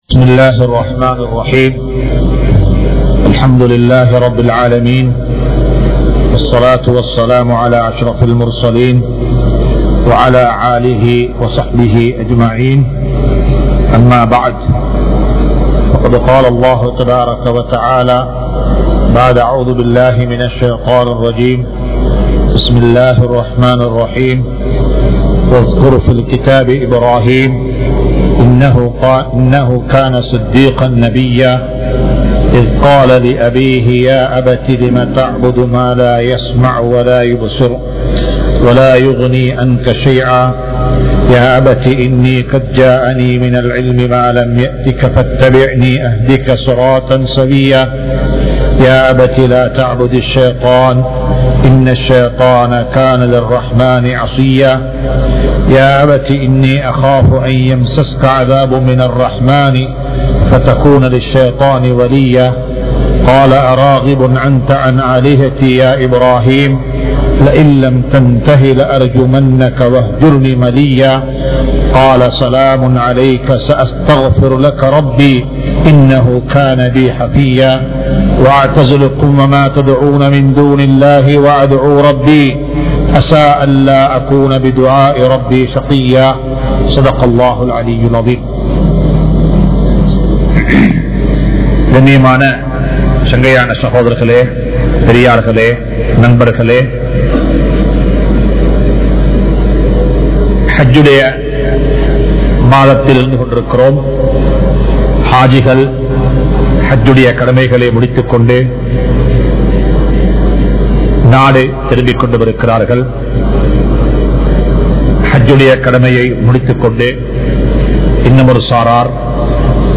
Importance Of Time | Audio Bayans | All Ceylon Muslim Youth Community | Addalaichenai